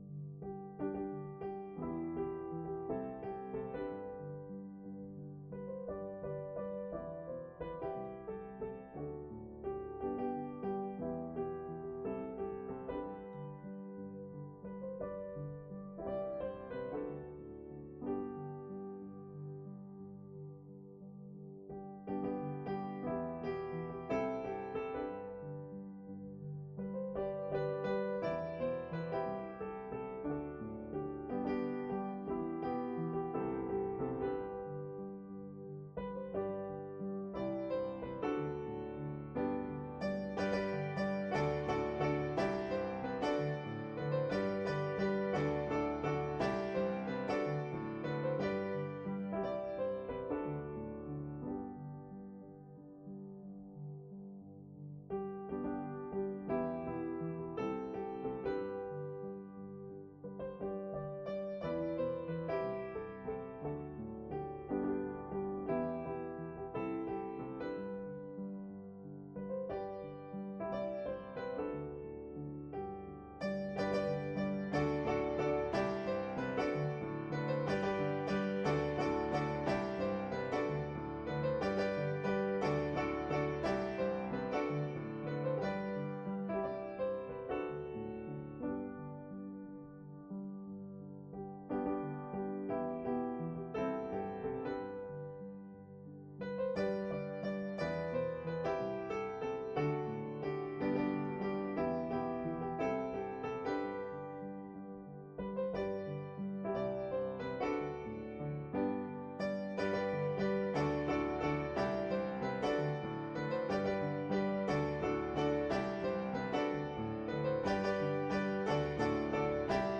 reprise au piano